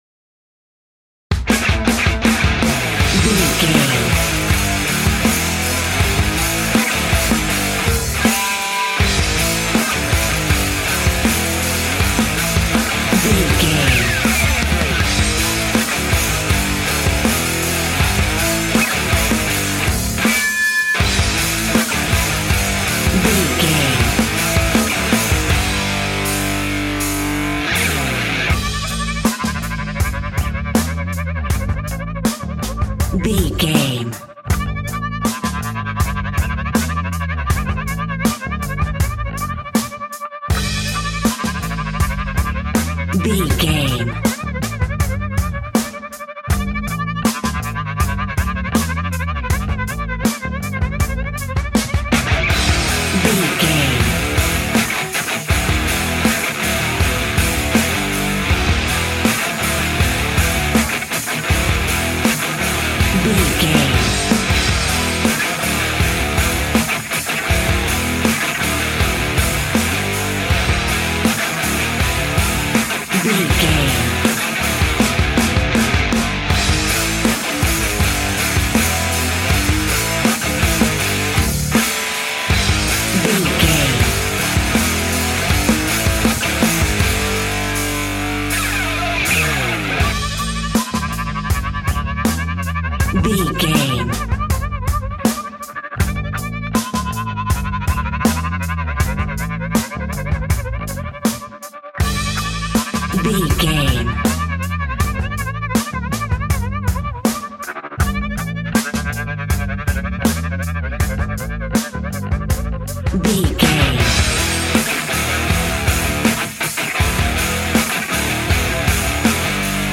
Epic / Action
Dorian
hard rock
heavy metal
Heavy Metal Guitars
Metal Drums
Heavy Bass Guitars